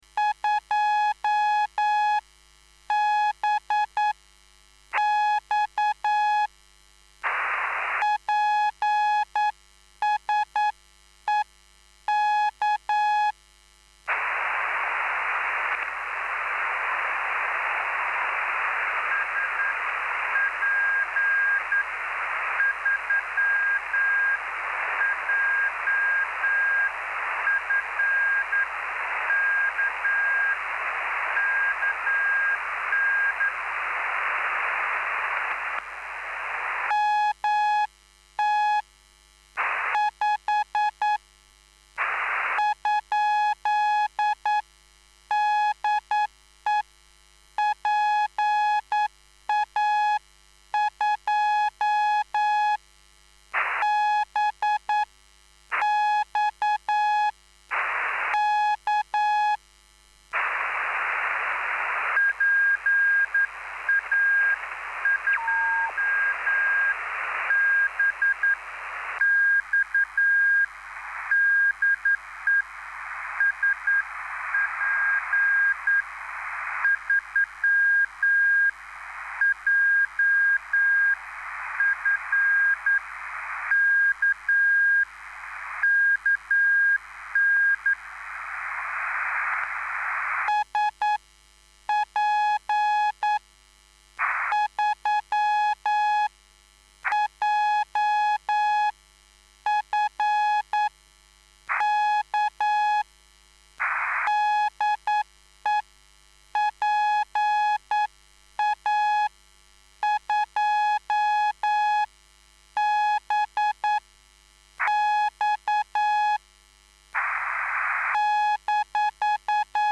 I can take a basic QSO in 8wpm now.